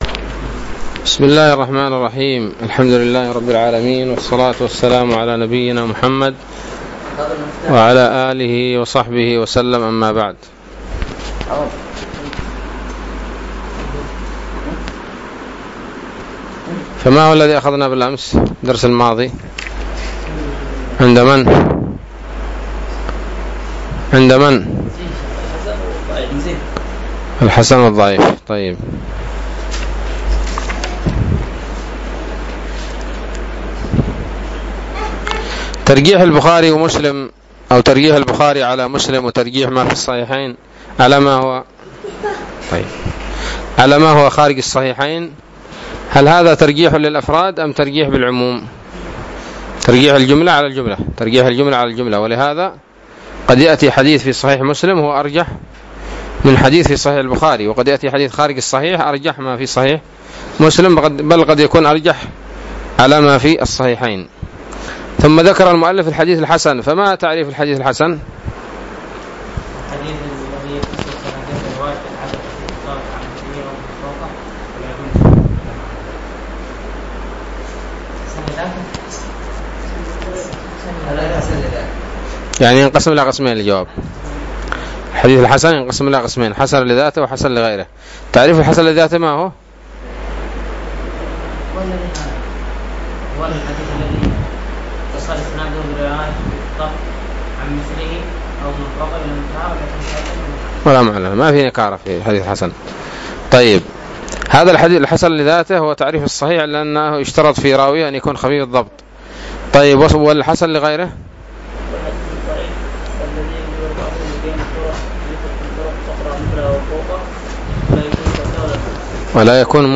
الدرس الثالث عشر من شرح نزهة النظر